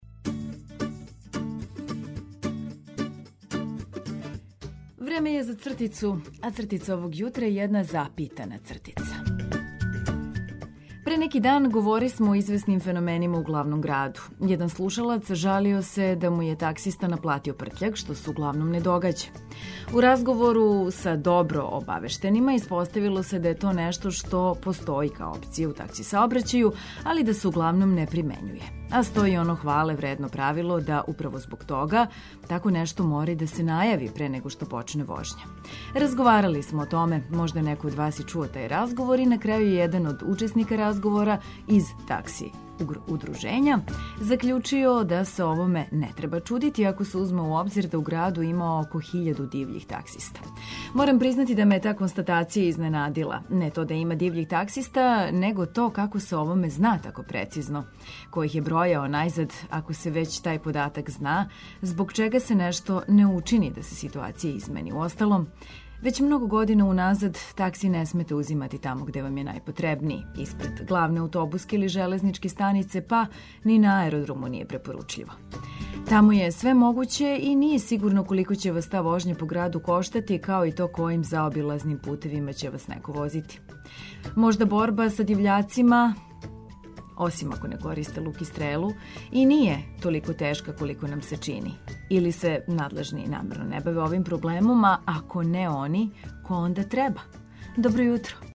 Крећемо ведро и распевано у нови дан.